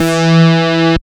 69.03 BASS.wav